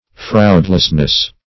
fraudlessness - definition of fraudlessness - synonyms, pronunciation, spelling from Free Dictionary
-- Fraud"less*ness , n. Fraudulence
fraudlessness.mp3